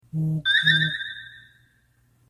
Category: Message Tones